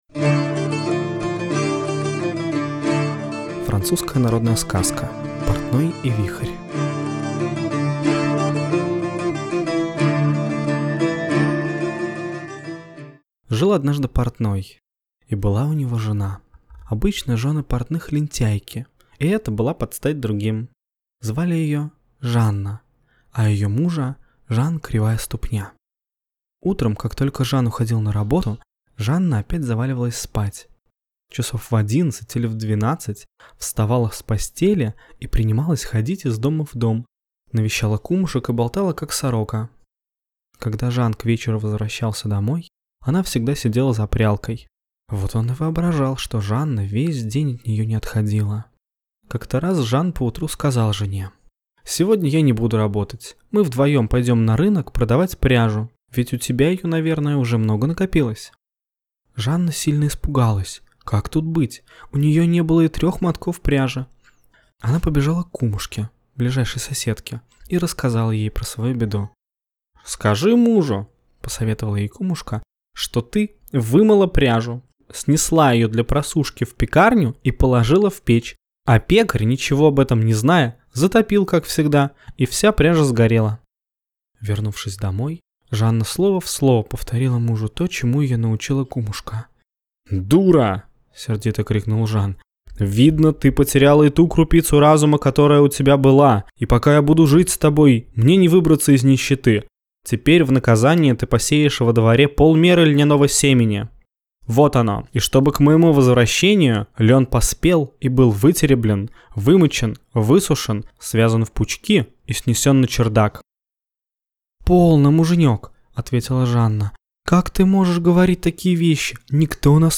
Портной и Вихрь - французская аудиосказка - слушать онлайн